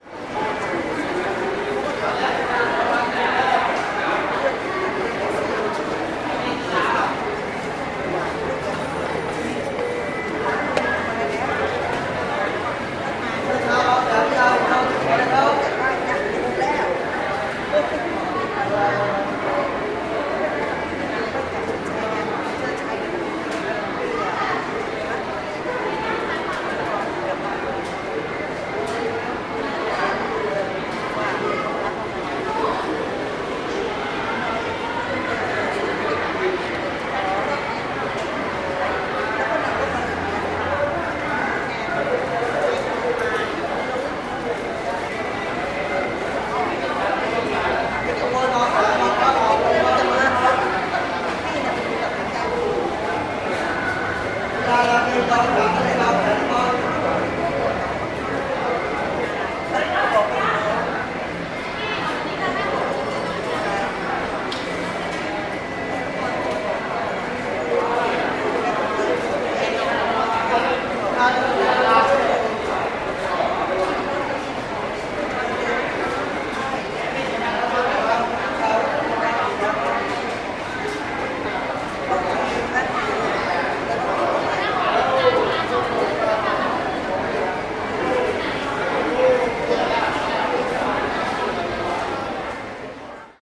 Звуки автовокзала